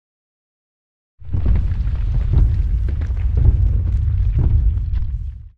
SA_quake.ogg